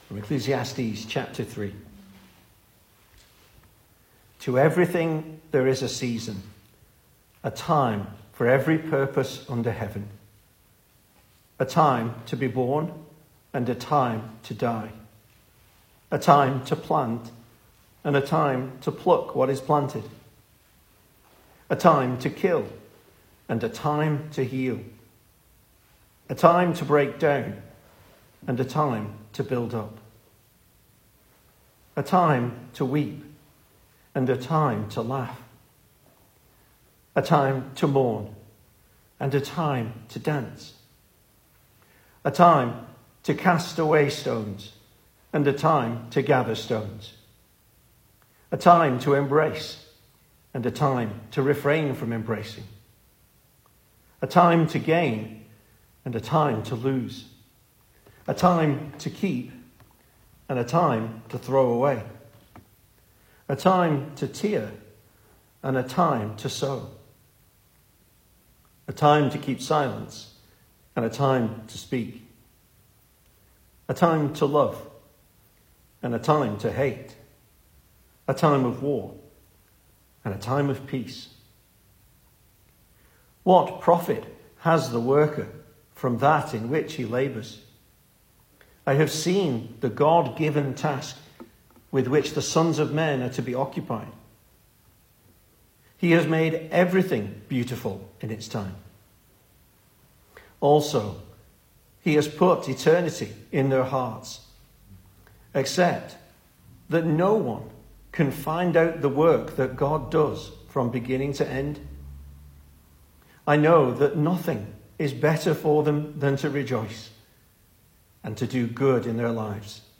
2025 Service Type: Weekday Evening Speaker